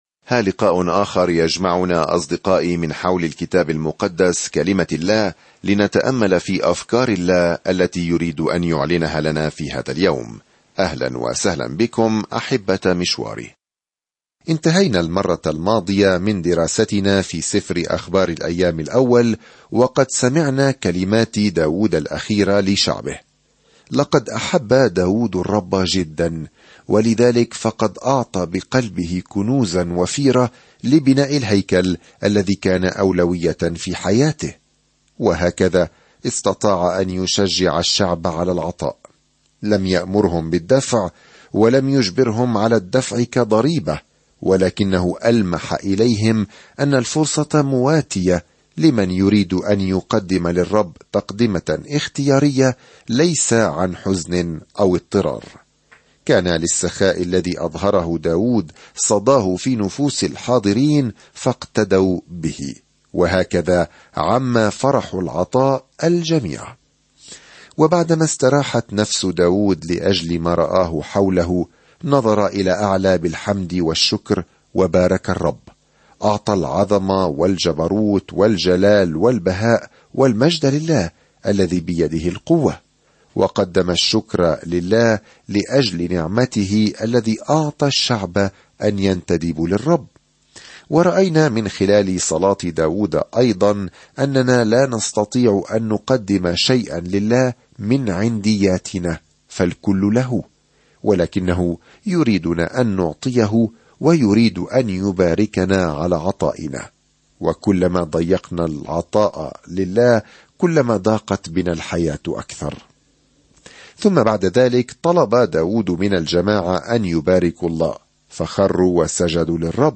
الكلمة أَخْبَارِ ٱلثَّانِي 1 أَخْبَارِ ٱلثَّانِي 1:2-5 ابدأ هذه الخطة يوم 2 عن هذه الخطة في أخبار الأيام الثاني، نحصل على منظور مختلف للقصص التي سمعناها عن ملوك وأنبياء إسرائيل السابقين. سافر يوميًا عبر أخبار الأيام الثاني بينما تستمع إلى الدراسة الصوتية وتقرأ آيات مختارة من كلمة الله.